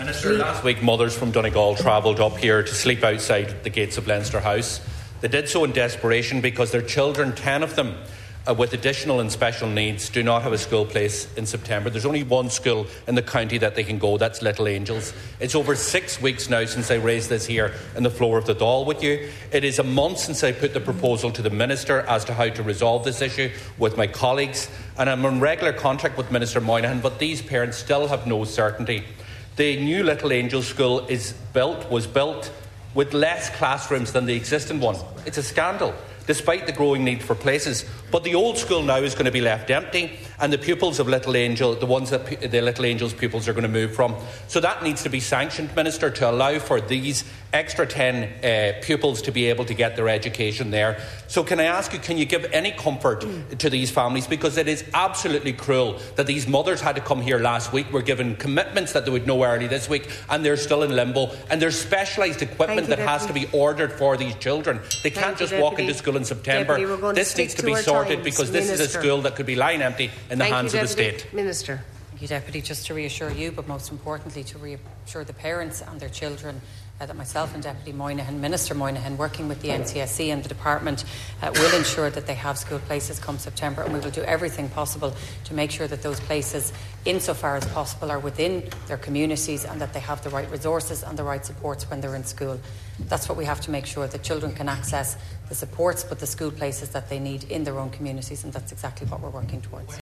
Minister McEntee says efforts are continuing to find a resolution: